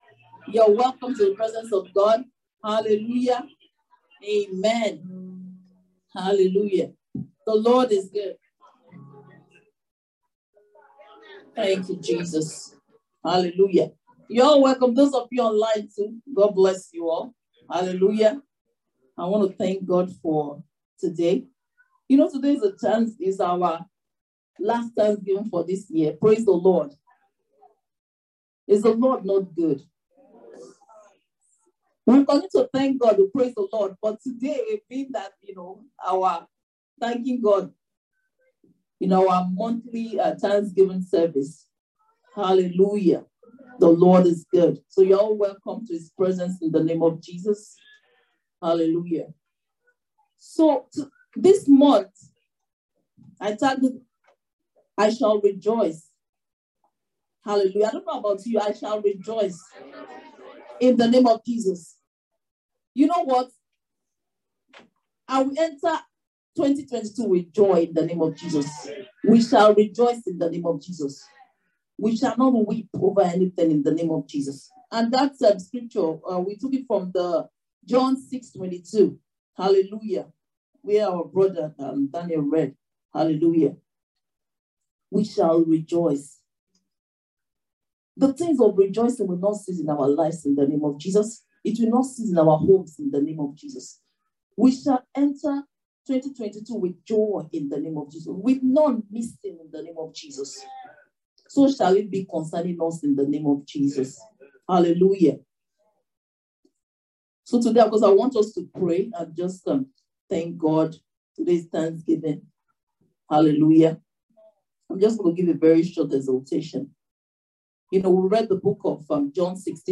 December-Thanksgiving.mp3